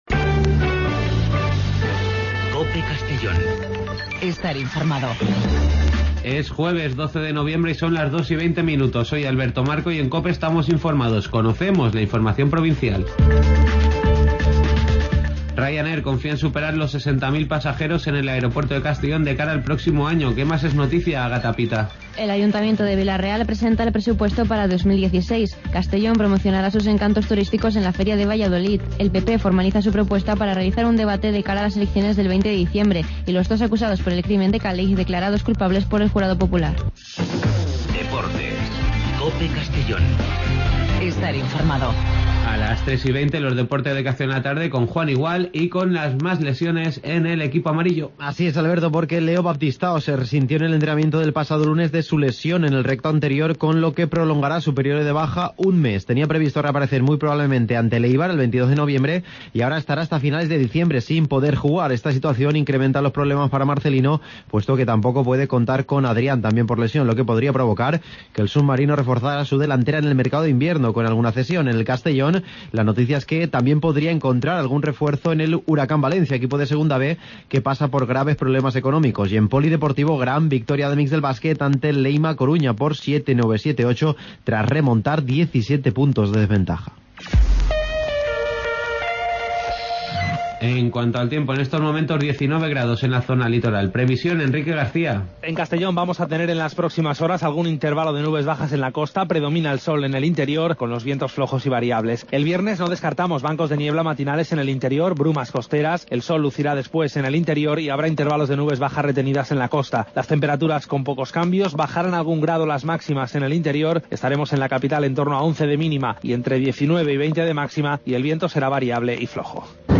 Espacio informativo a nivel provincial, con los servicios informativos de COPE en la provincia de Castellón.